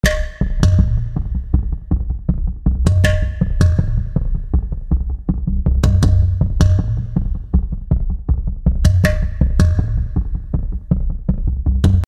Von Takt 1 bis 8 tut sich ja nicht wirklich viel.
So hörte sich das Intro ohne Envolution an: